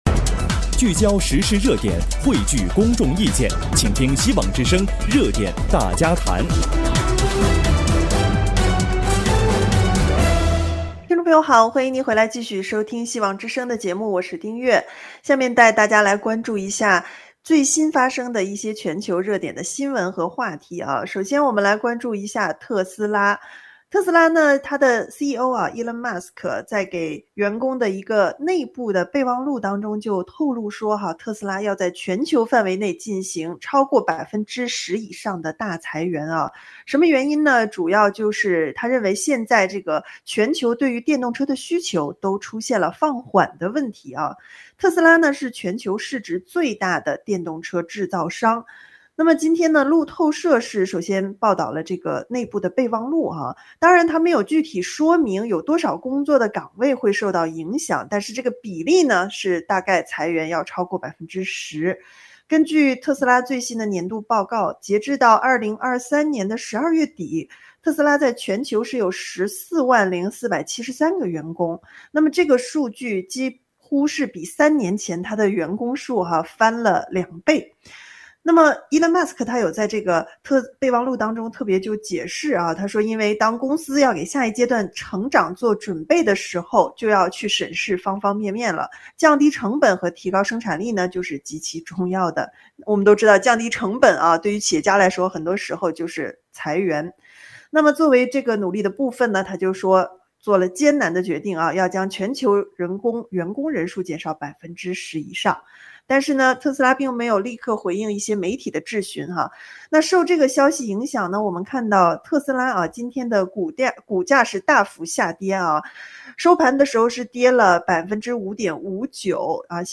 【希望之聲2024年4月16日】（主持人